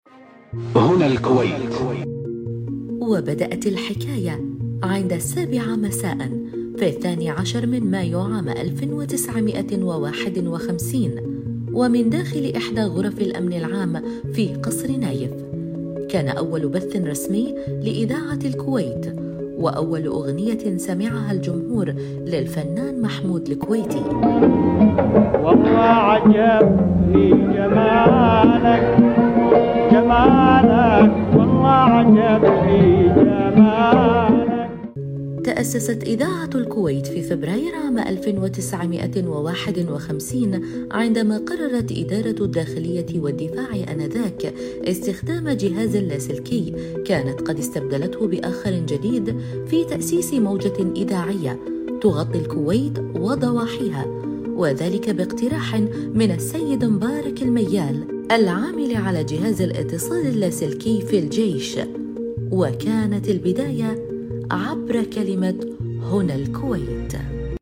وثائقي – تأسيس إذاعة الكويت